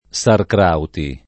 sarcrauti [ S arkr # uti ]